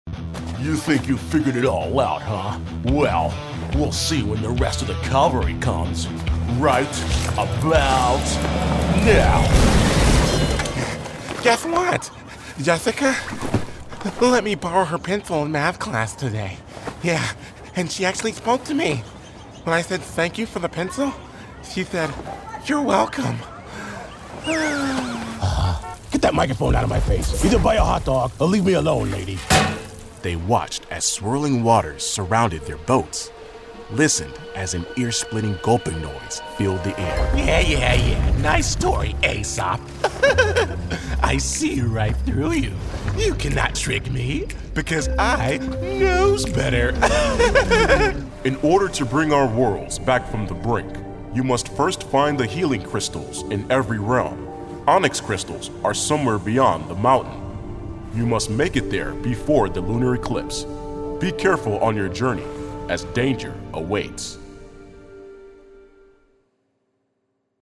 English (American)
Deep, Urban, Friendly